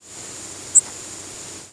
lazy: Generally refers to a call that starts softly and builds to a higher volume toward the middle or end (e.g.,
Magnolia Warbler). See "abrupt".